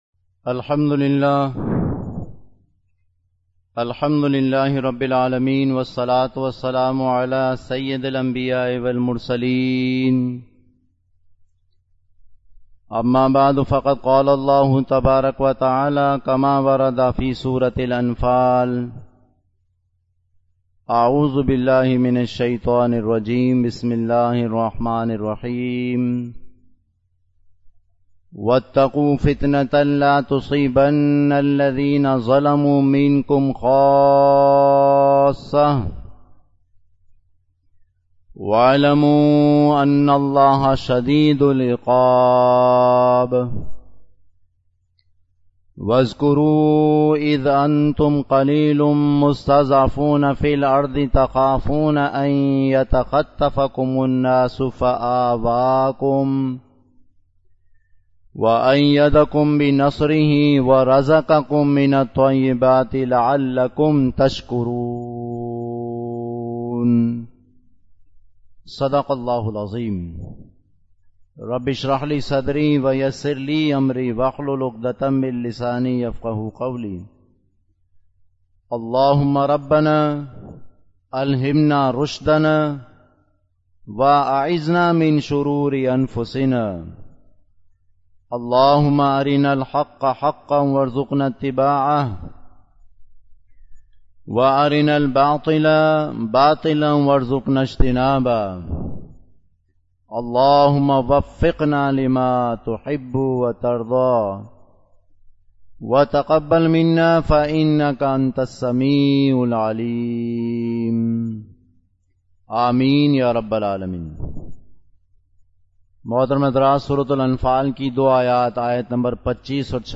Khutbat-e-Jummah (Friday Sermons)